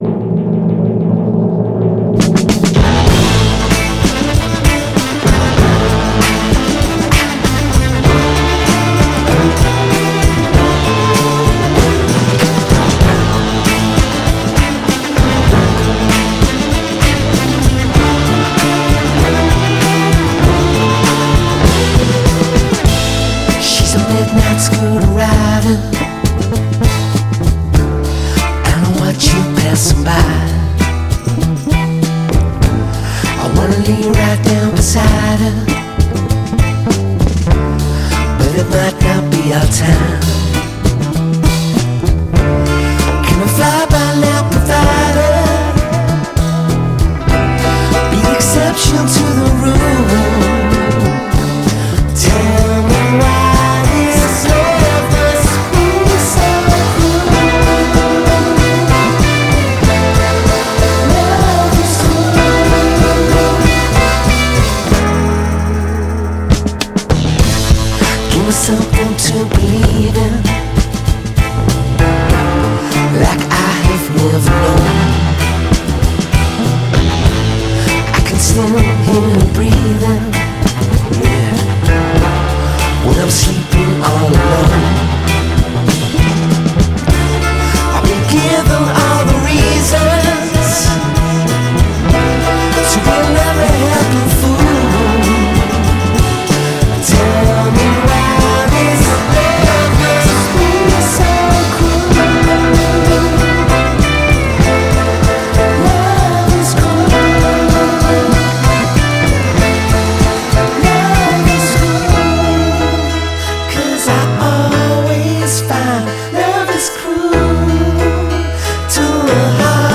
Альбом - в стиле психо-рок-н-ролл.